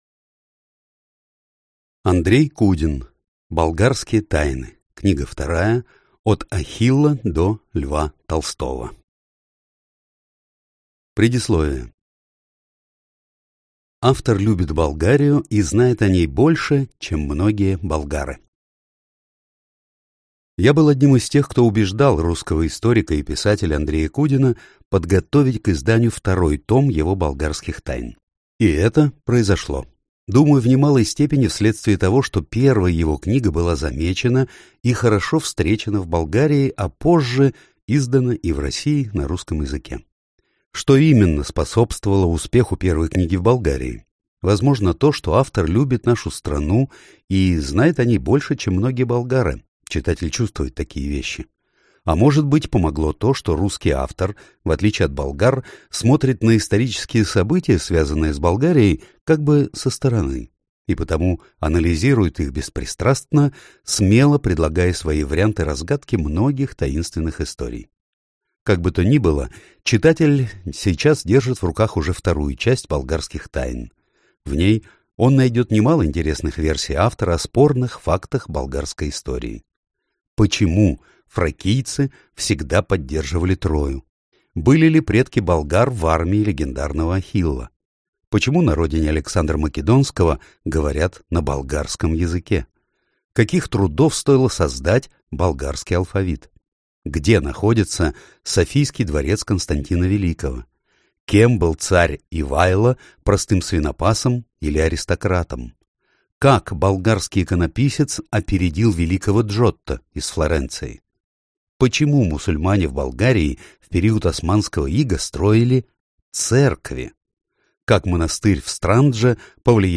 Аудиокнига Болгарские тайны. От Ахилла до Льва Толстого | Библиотека аудиокниг